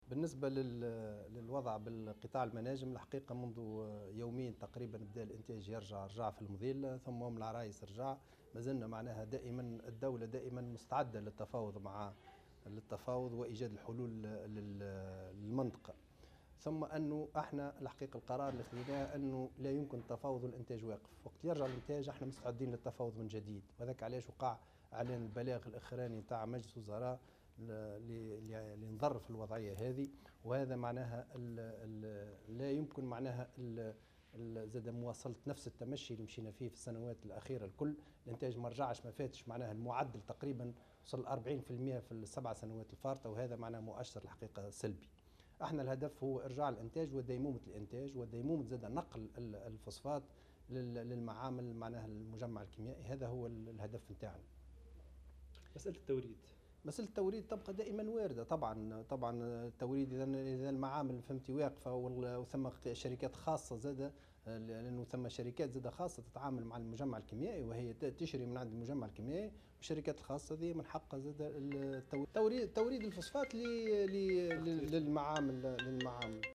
قال وزير الطاقة خالد قدور في تصريح لمراسل الجوهرة "اف ام" اليوم 5 مارس 2018 إن الوزارة مستعدة دائما للتفاوض و ايجاد الحلول للمنطقة مع المعتصمين ولكن لا يمكنها التفاوض طالما أن الانتاج متوقف حسب قوله.